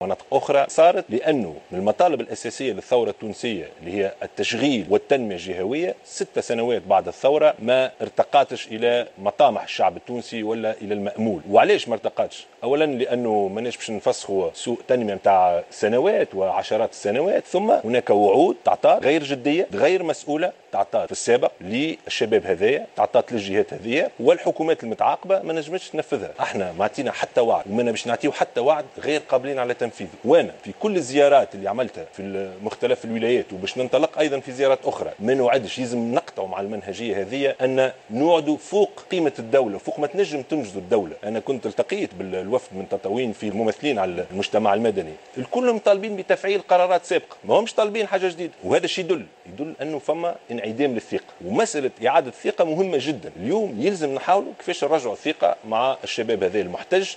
وأضاف الشاهد في حوار على قناة الوطنية الأولى، أن الوضع الاقتصادي الوطني صعب، وتدهور النمو والاستثمار سبب انعدام فرص الشغل، مشيرا إلى أن حكومته اتخذت إجراءات وقتية على غرار عقد الكرامة لخلق فرص شغل للشباب في انتظار قرارات جدية.